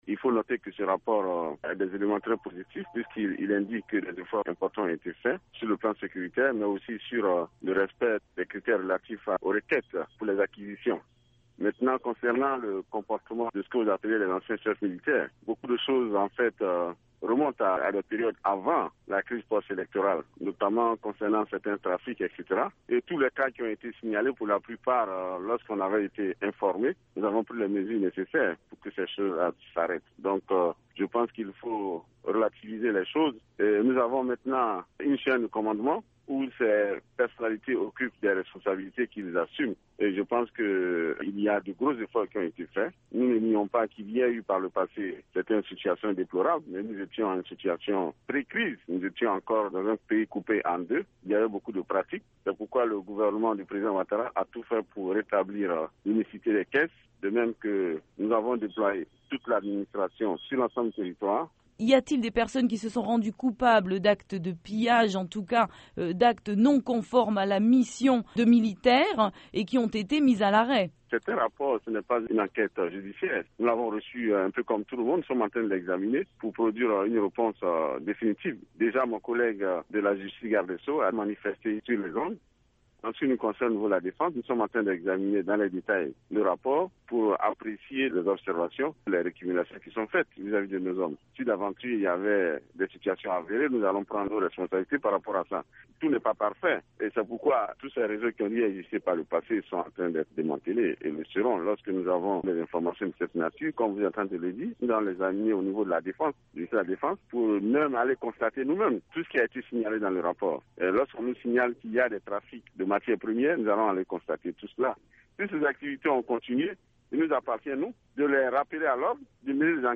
Le ministre Paul Koffi Koffi